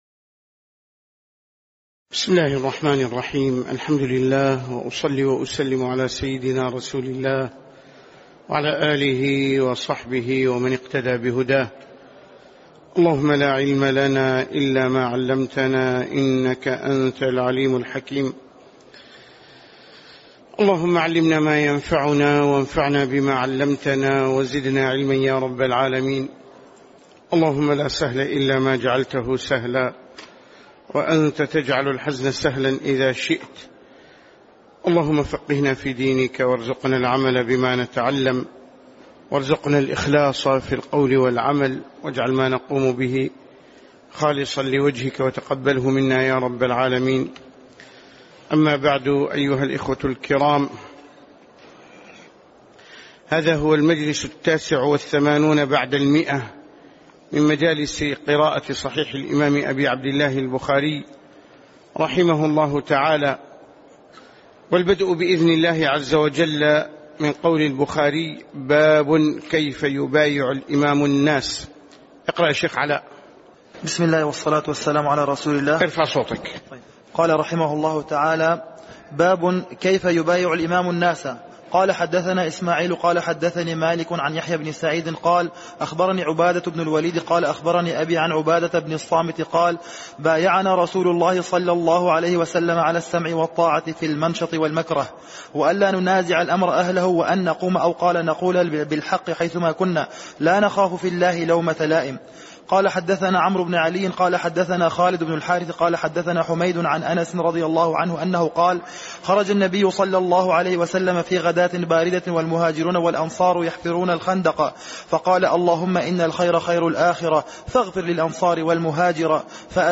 تاريخ النشر ٢٤ ربيع الأول ١٤٣٩ هـ المكان: المسجد النبوي الشيخ